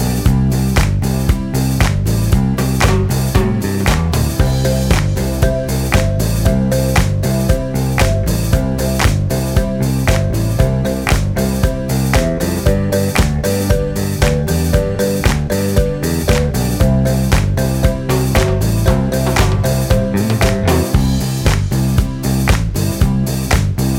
no Backing Vocals Disco 4:05 Buy £1.50